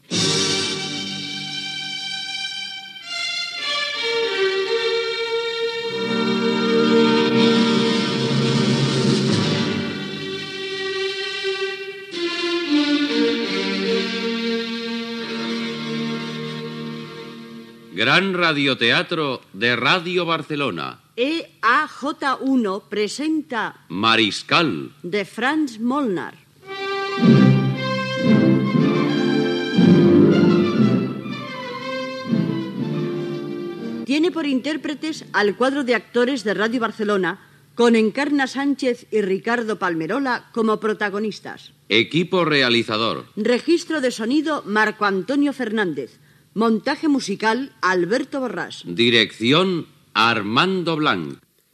Adaptació radiofònica de l'obra "Mariscal", de Molnar.
Careta del programa.